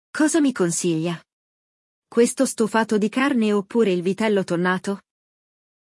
Neste episódio, vamos ouvir uma cliente em um restaurante pedindo ajuda a um garçom para fazer o pedido.
Il dialogo